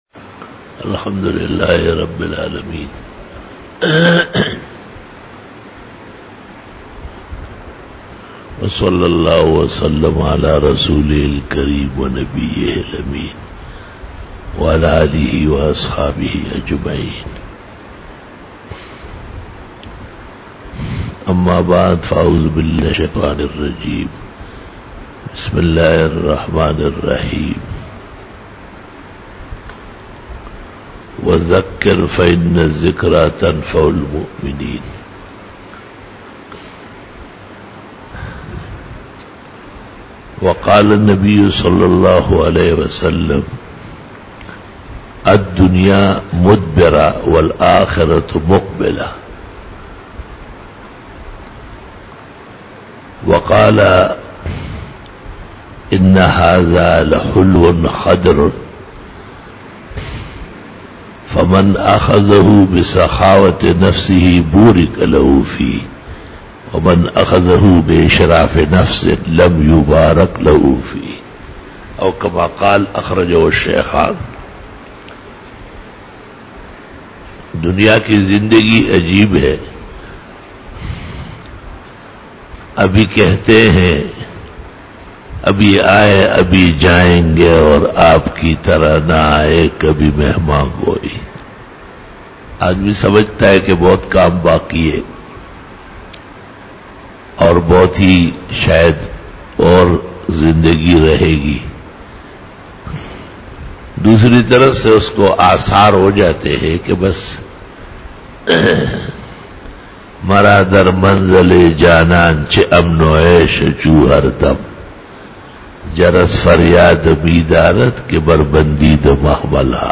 14 BAYAN E JUMA TUL MUBARAK (05 April 2019) (28 Rajab 1440H)
Khitab-e-Jummah 2019